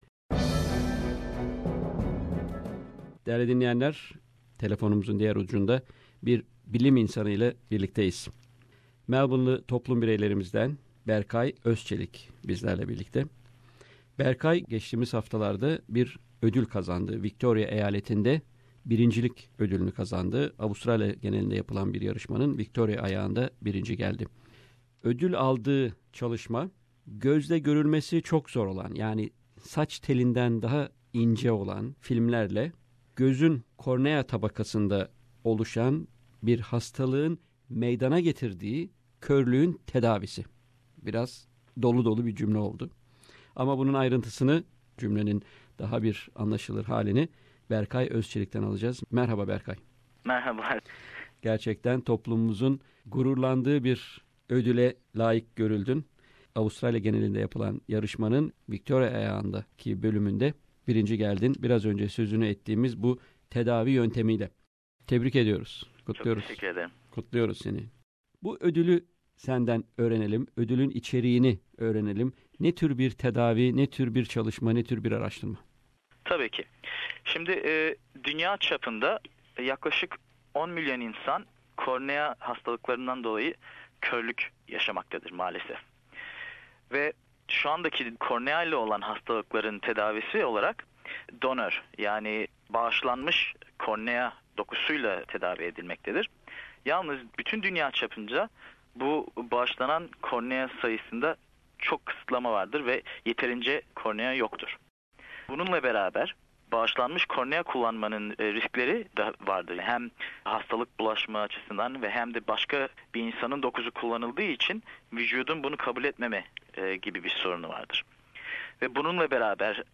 bir röportaj gerçekleştirdi.